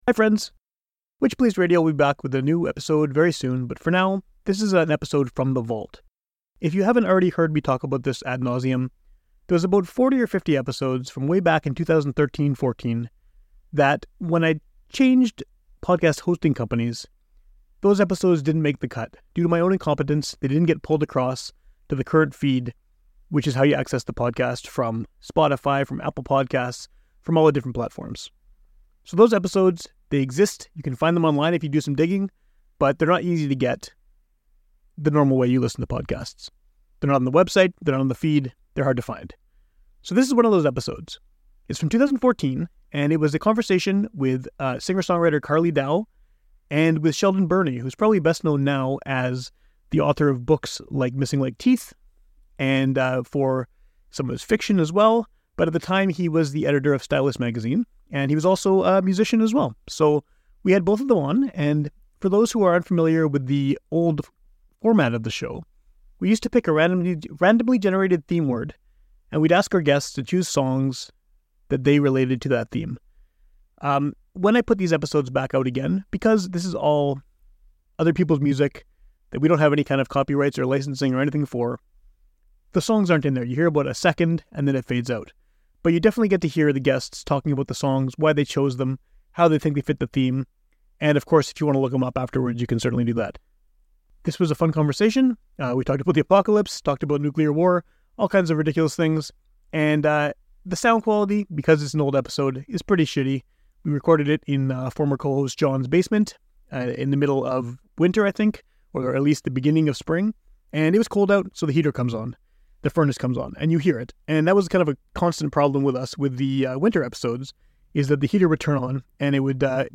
This one is particularly notable because it has absolutely deadly live performances by each of the guests.